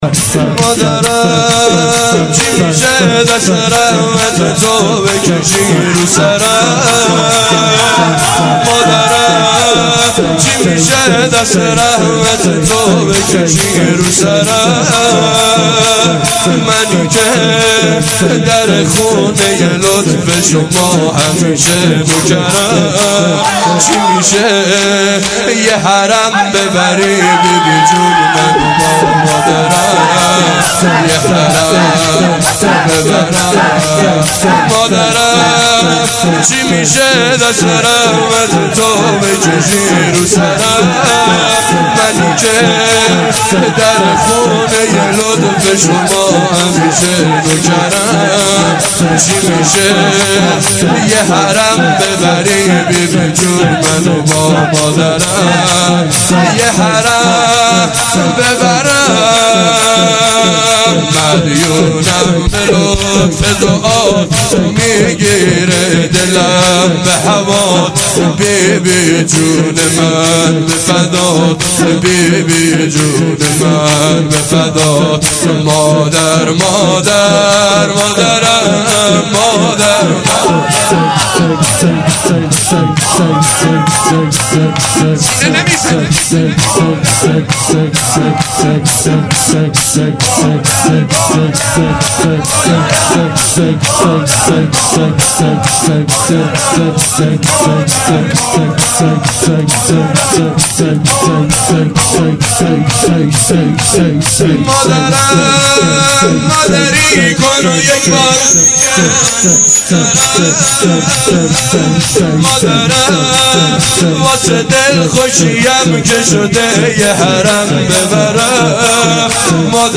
شور - فاطمیه - 95